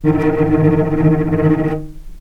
vc_trm-D#3-pp.aif